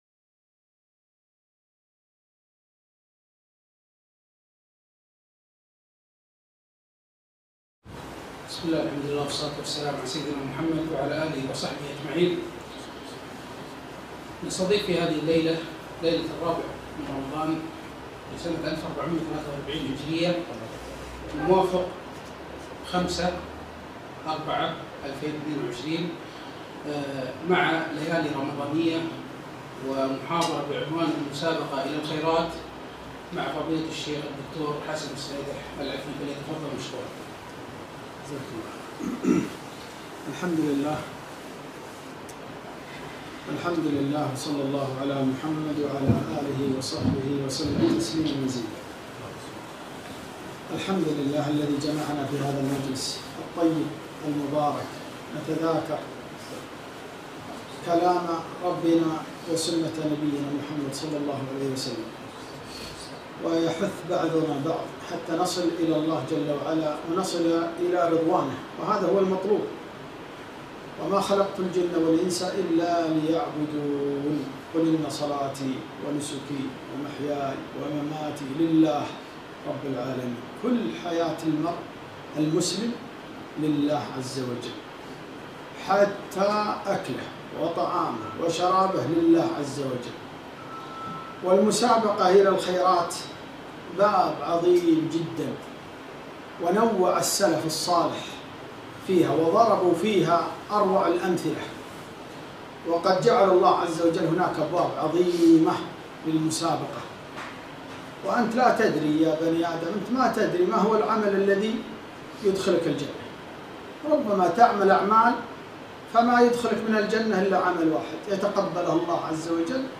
محاضرة - المسابقة إلى الخيرات